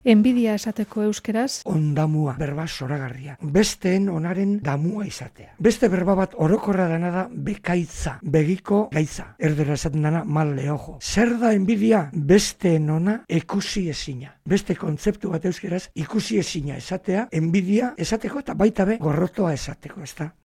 Os traemos varias maneras para expresar envidia en bizkaiera.